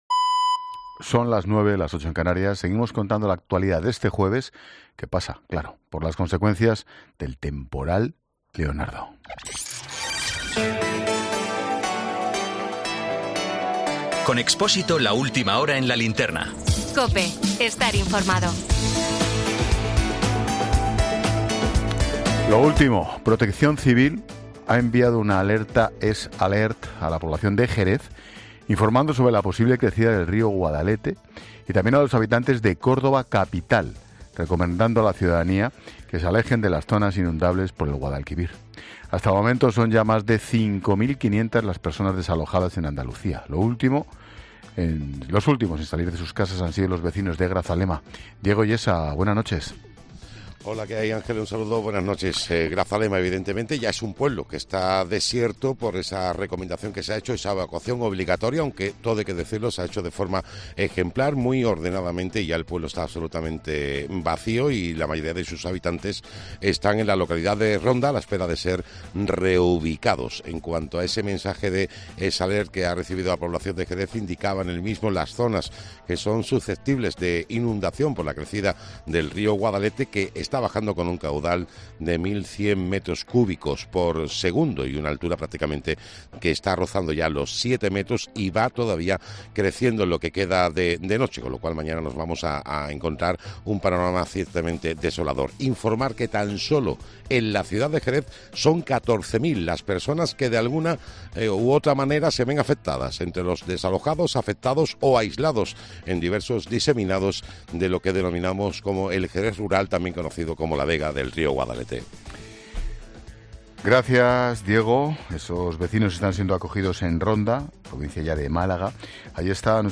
En el programa ' La Linterna ' de COPE, con Ángel Expósito, se ha analizado la situación de las infraestructuras hídricas , puestas a prueba por las intensas lluvias.